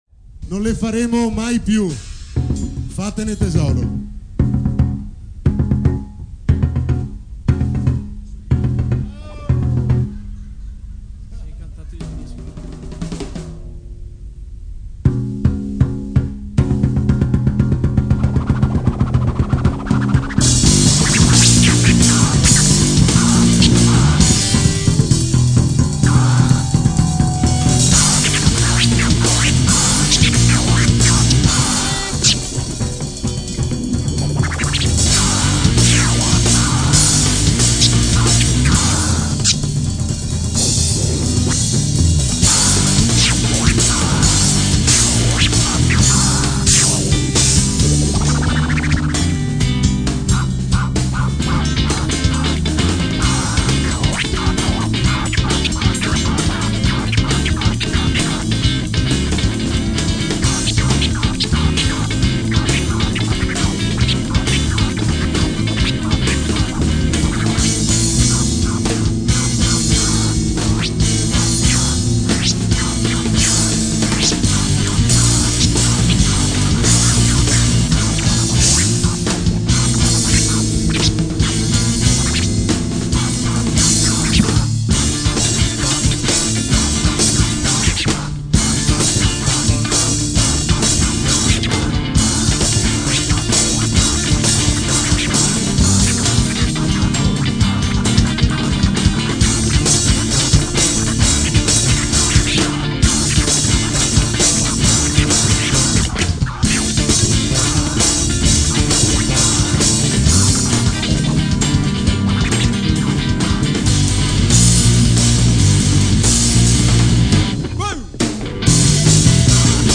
27/09/2006 Il concerto perfetto, tutto a regime in bomba.